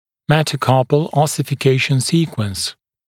[ˌmetə»kɑːpl ˌɔsɪfɪ’keɪʃn ‘siːkwəns][ˌмэтэ’ка:пл ˌосифи’кейшн ‘си:куэнс]последовательность оссификации пястных костей